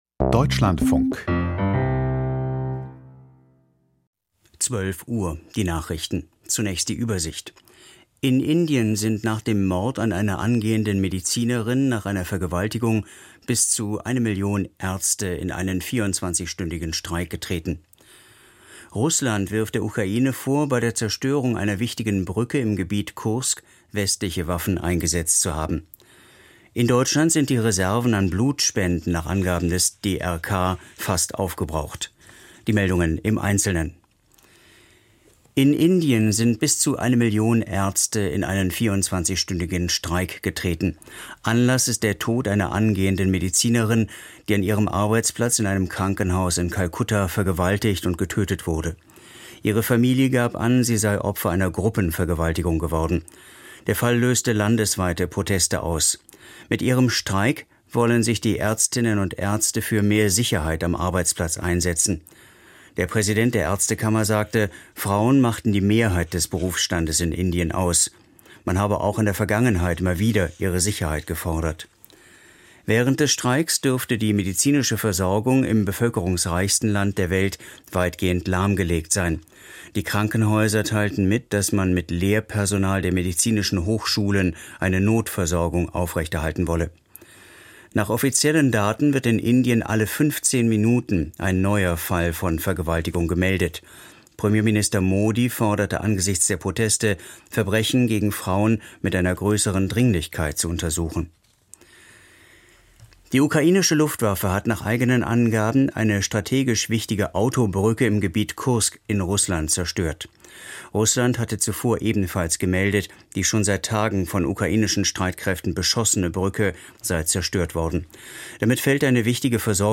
Zum Bundeshaushalt - Interview mit Sven-Christian Kindler (Grüne) - 17.08.2024